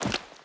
Footsteps / Water / Water Land.wav